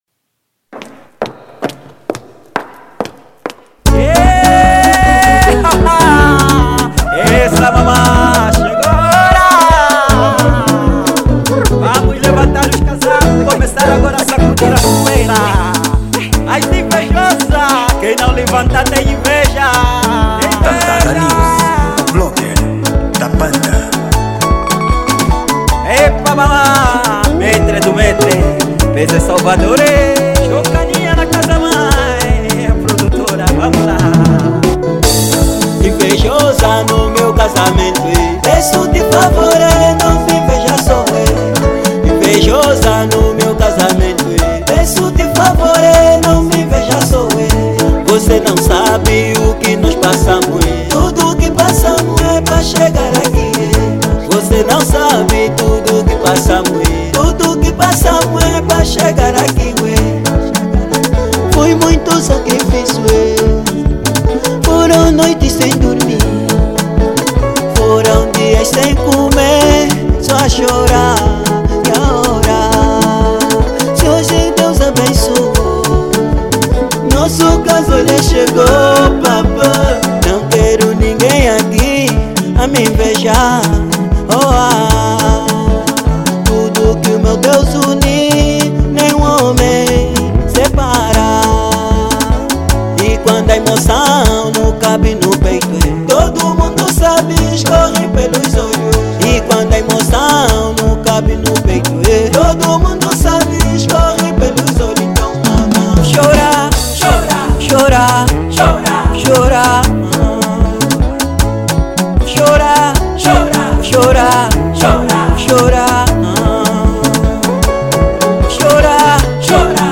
Gênero: Sungura